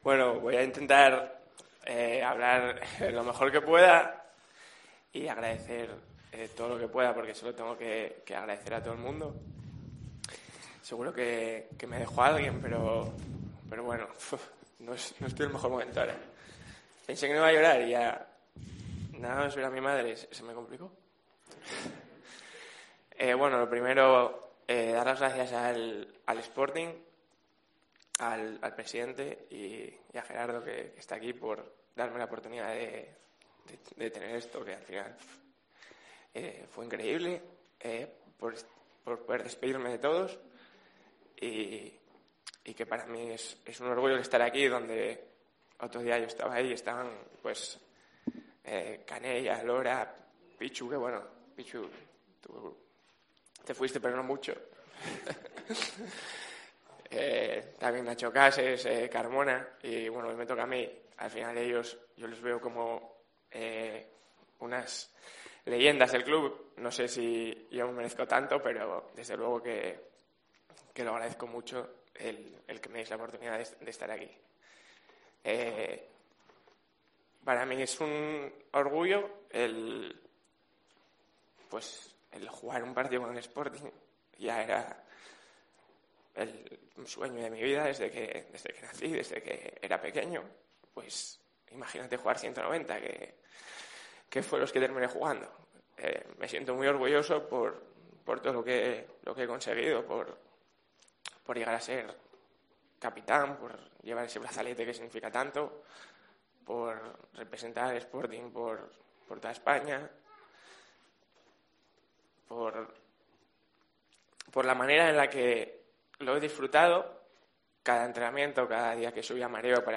El jugador gijonés se despide en un emotivo acto en El Molinón, en el que estuvo acompañado de familiares, amigos, plantilla y ex compañeros